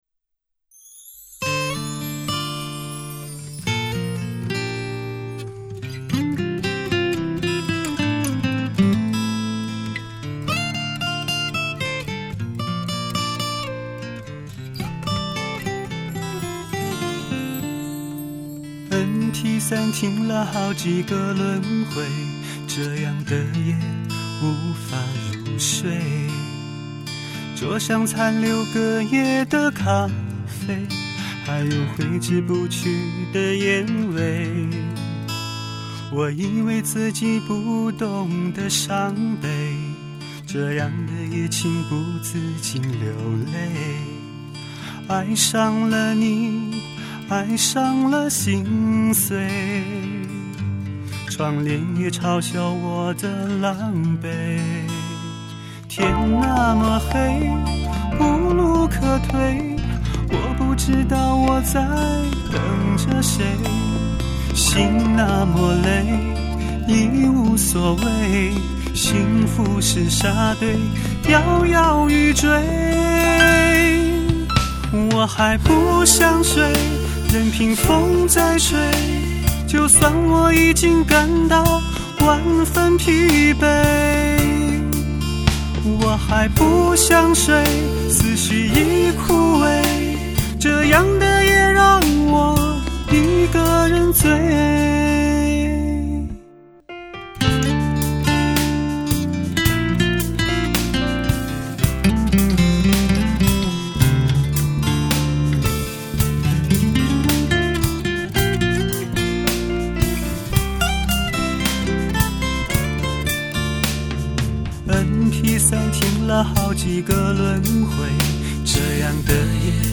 这首歌旋律流畅，歌词感人，虽没有时下流行的音乐元素，却是一首能够真正打动人心的好歌！
唱功不俗，结尾处的几个转音演绎地自然流畅